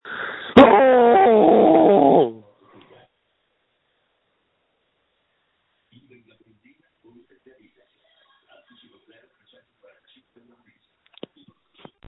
JUST SCREAM! Screams from December 1, 2020
• When you call, we record you making sounds. Hopefully screaming.